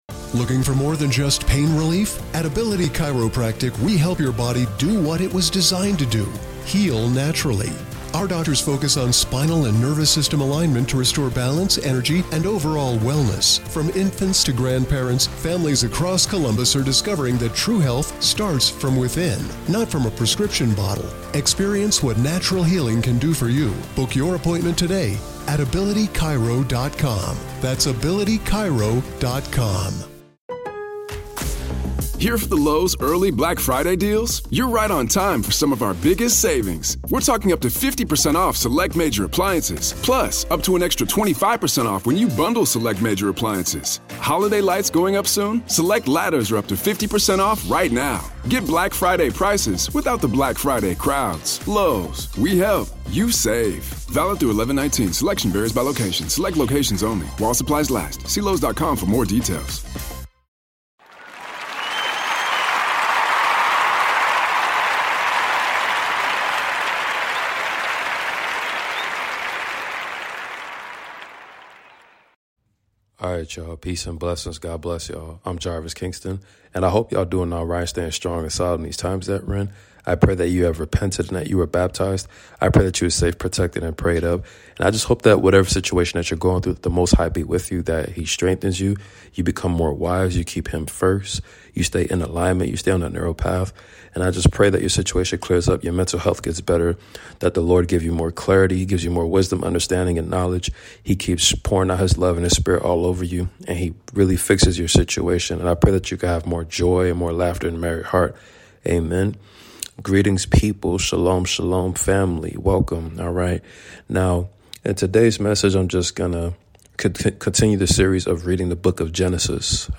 Continued reading of Genesis let’s stay strong and pray !!!!!